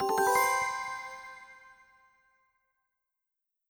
win.wav